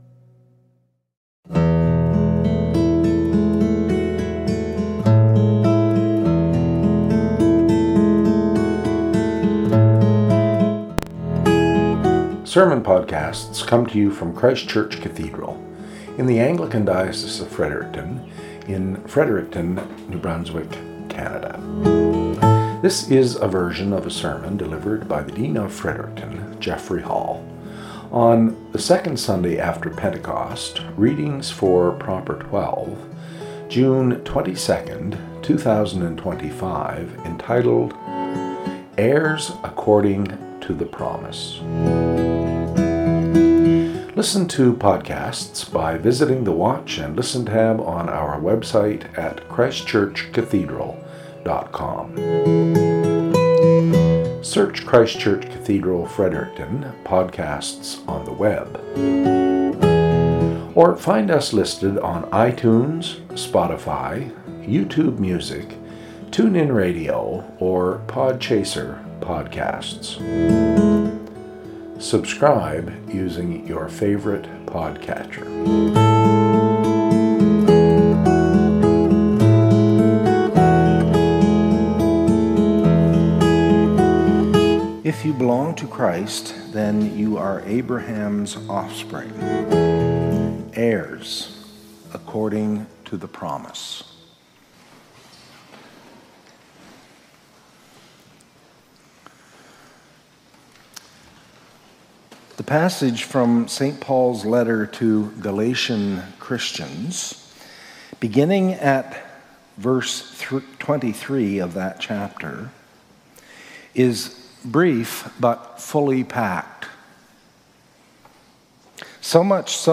Cathedral Podcast - SERMON -
Podcast from Christ Church Cathedral Fredericton